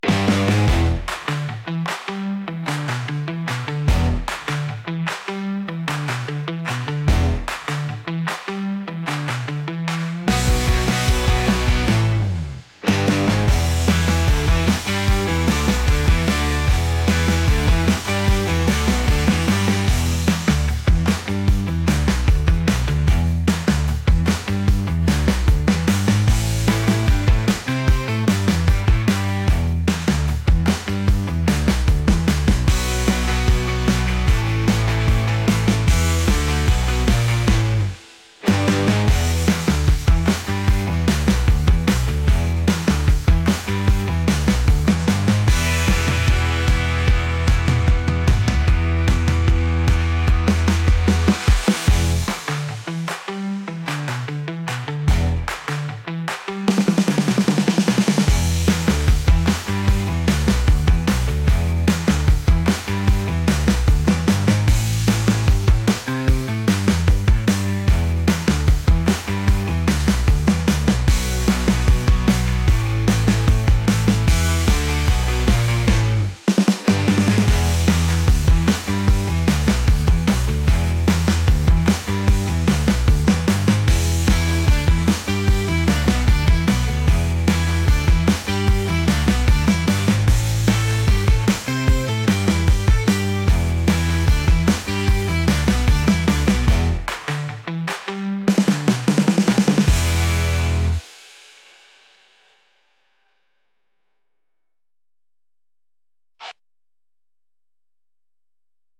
energetic | rock